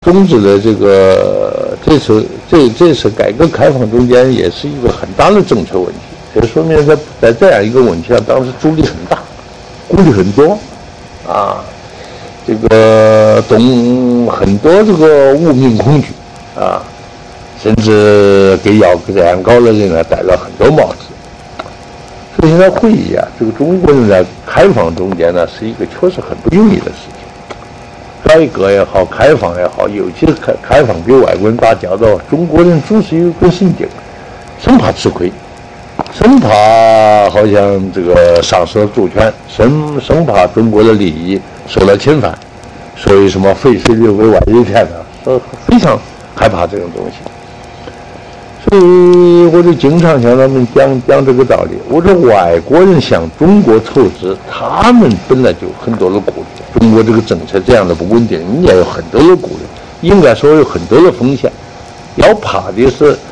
赵紫阳录音回忆录《改革历程》节选之七